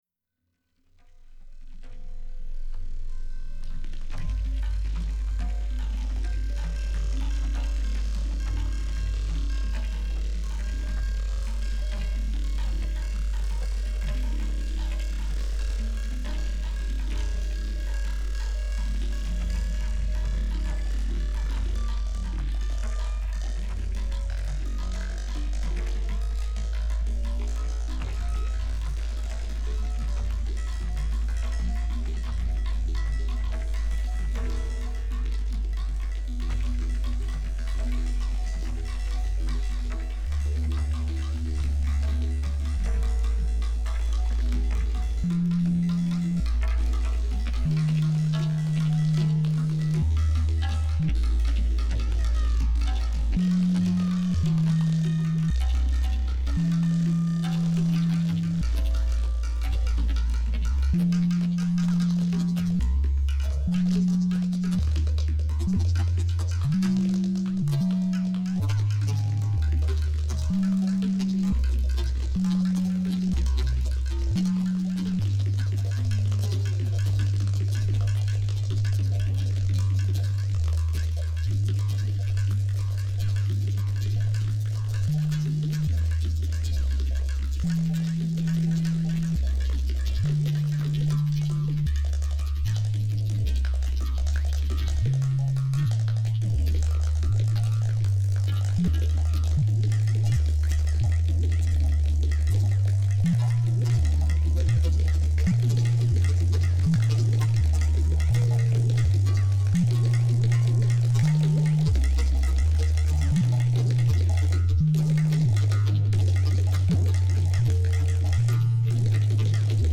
RunningTabla.mp3